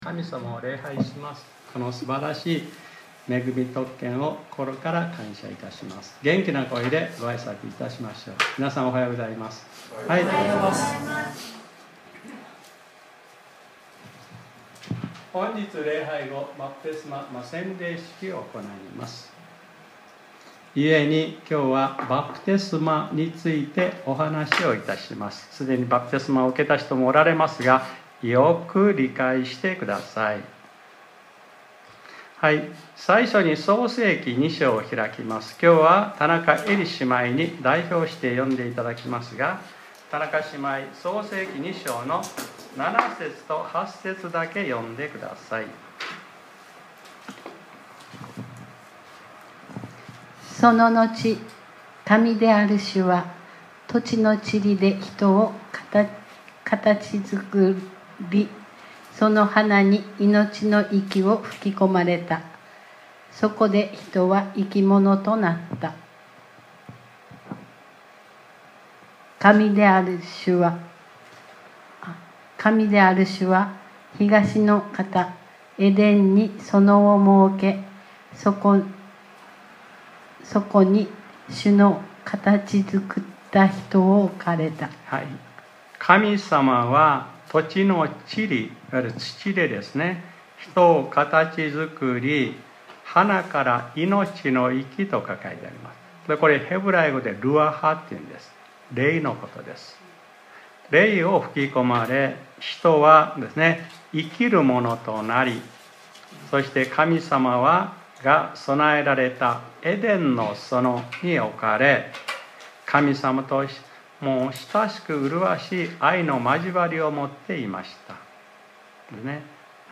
2025年05月18日（日）礼拝説教『 洗礼式：バプテスマ 』